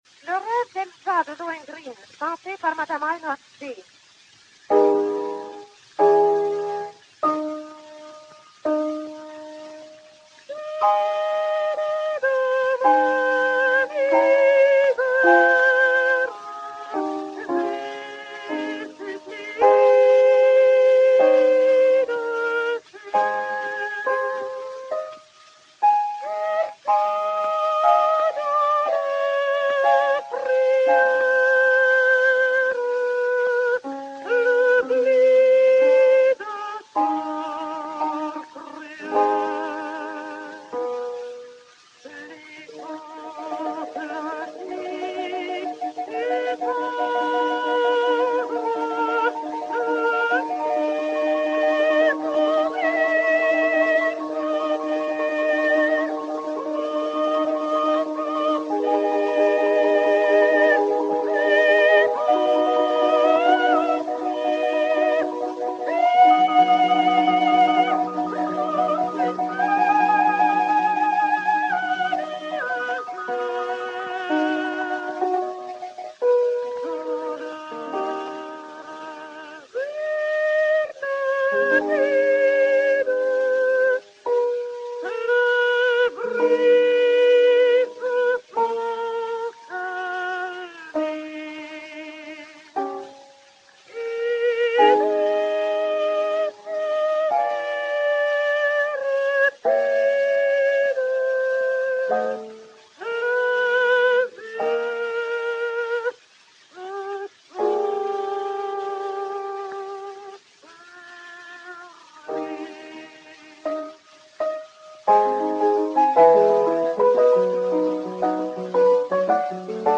soprano finlandais
Aïno Ackté (Elsa) et Piano
Fonotipia 39089, mat. 36872, enr. à Paris en 1905